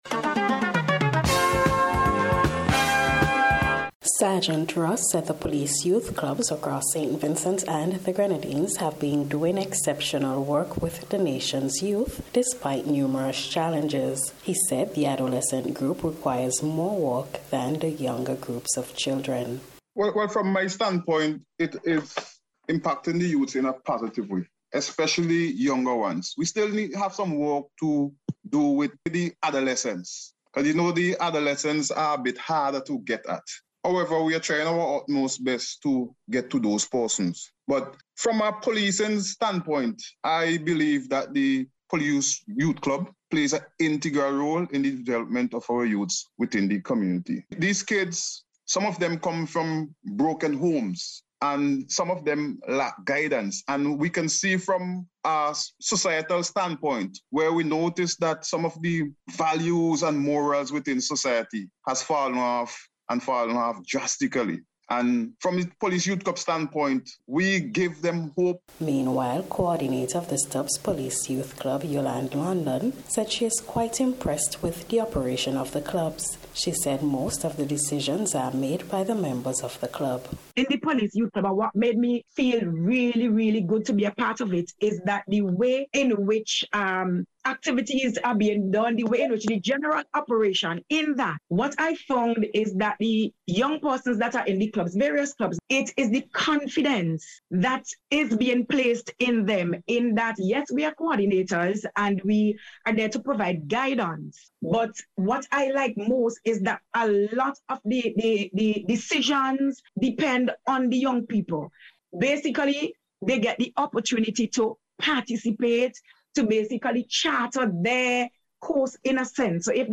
NBC’s Special Report – Tuesday September 27th 2022